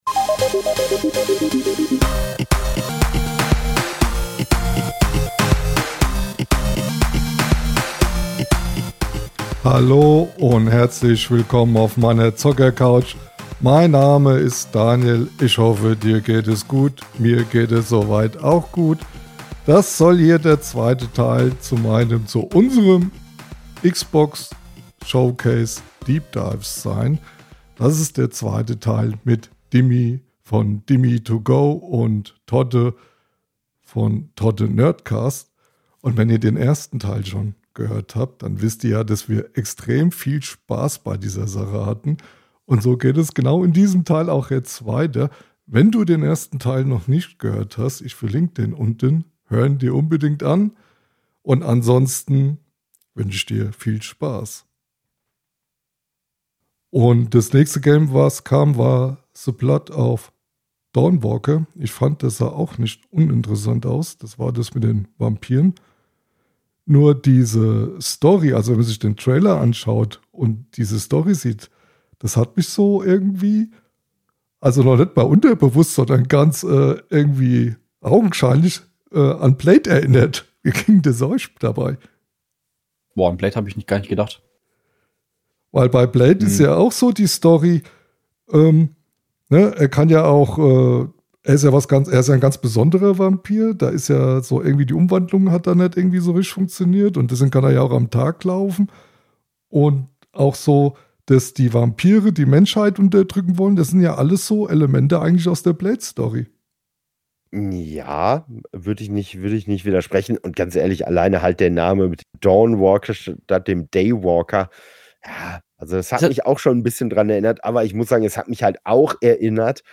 Wir sprechen über unsere Favoriten, Überraschungen und die Titel, die für Gesprächsstoff sorgen. Freut euch auf neue Einblicke, spannende Diskussionen und unsere persönlichen Meinungen zu den Highlights des Events.